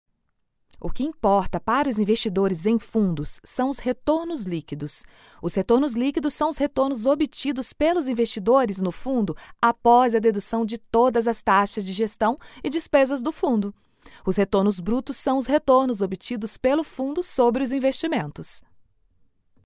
Voces profesionales brasileñas.
locutora Brasil, Brazilian voice over